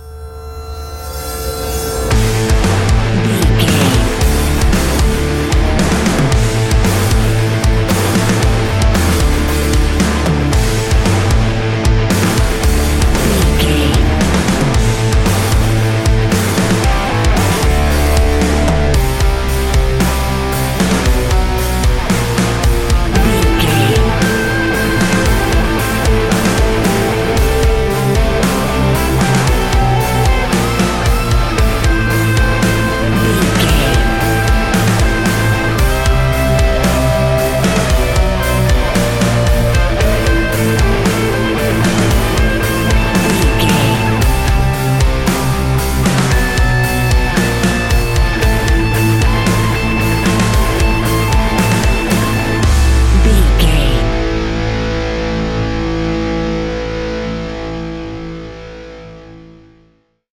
Ionian/Major
A♭
hard rock
guitars
instrumentals